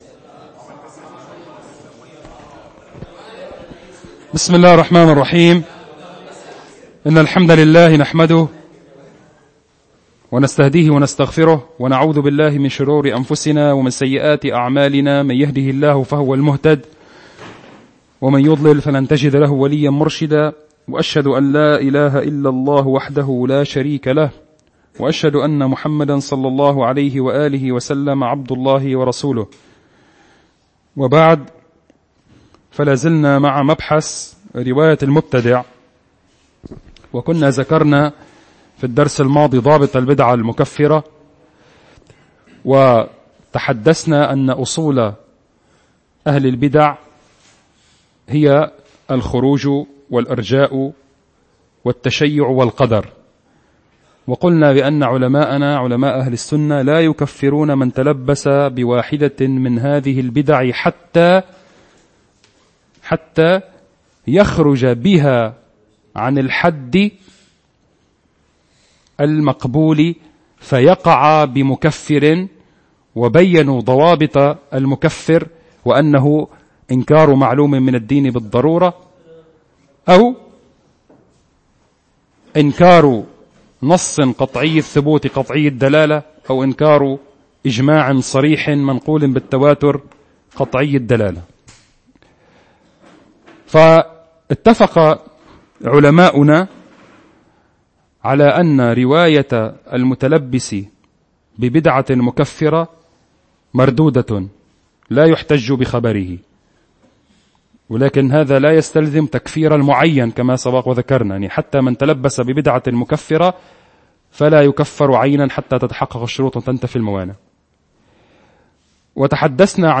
المكان : مركز جماعة عباد الرحمن
الموضوع : صفات من لا تقبل روايته من الطبقات التي بعد الصحابة (4) ملاحظة : بسبب خلل تقني، نقص من نهاية التسجيل 5 دقائق.